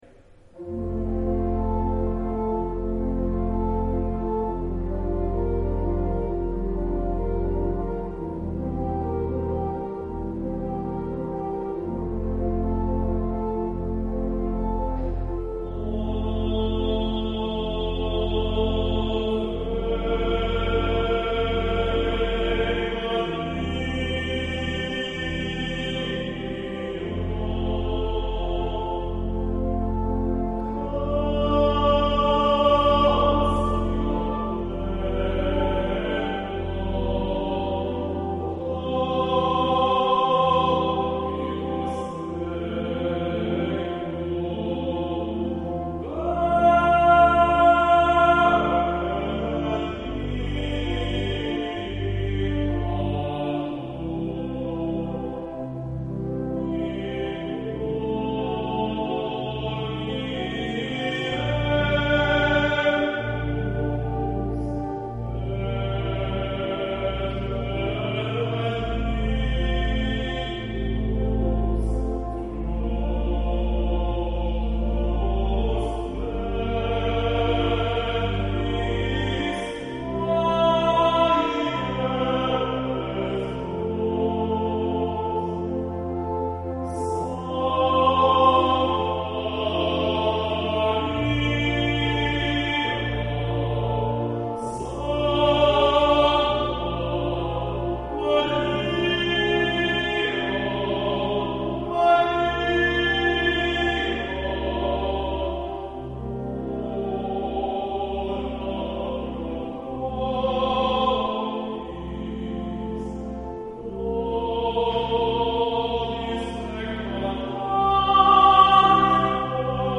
Ave Maria (Bach,Gounod) (with Pipe Organ)
Ave-Maria-BachGounod-with-Pipe-Organ.mp3